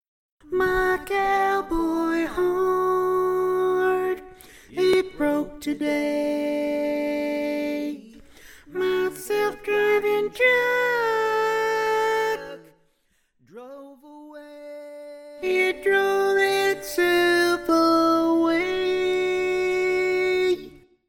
Key written in: D Major
How many parts: 4
Type: Barbershop